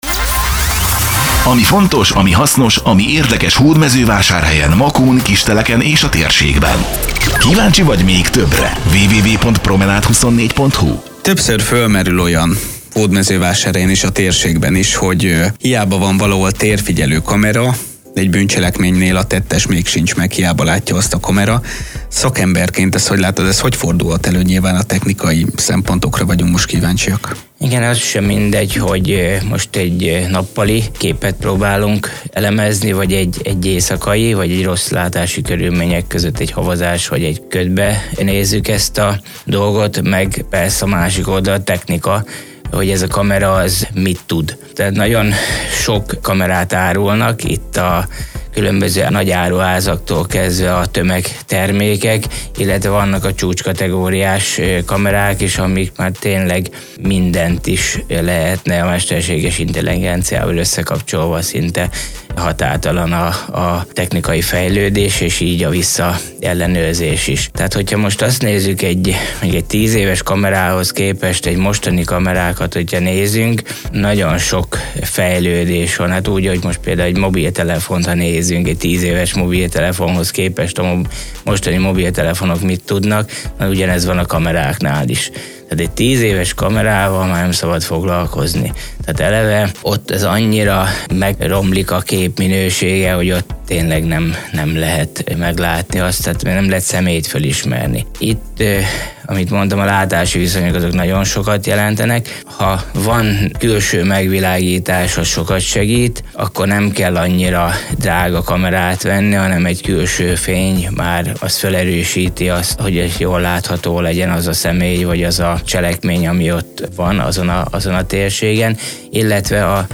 Promenad24 - Rádió 7 Podcast - Mitől függ, hogy mennyit lát egy térfigyelő kamera?